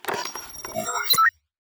calibrate good.wav